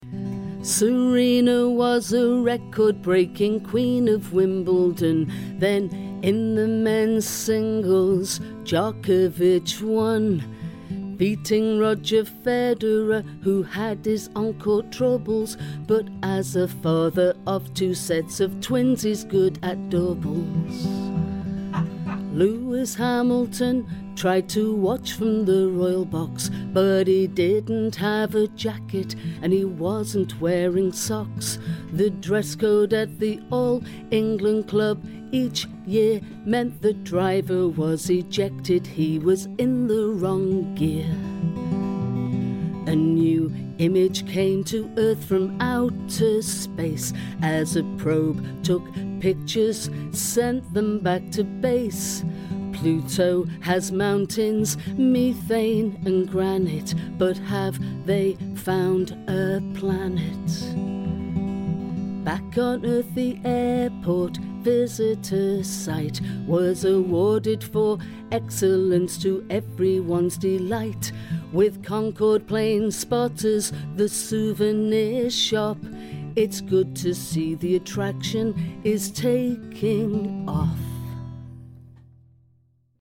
news in song